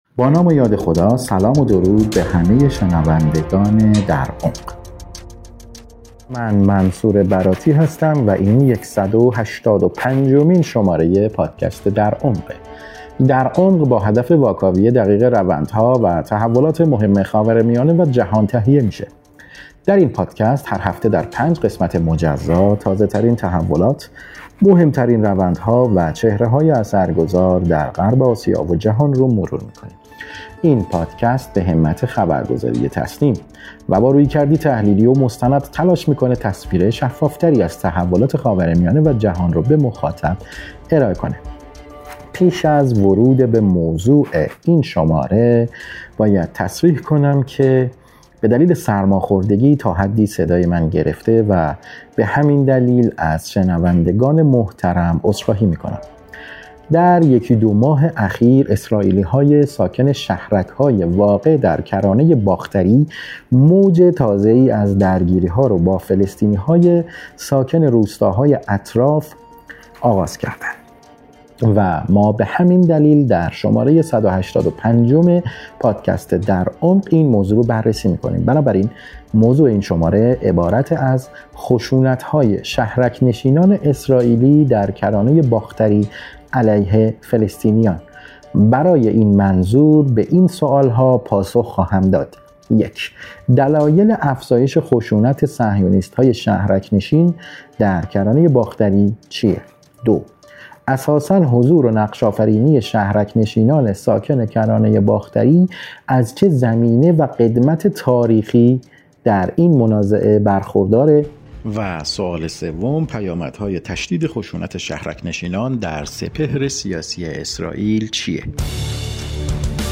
کارشناس مسائل رژیم صهیونیستی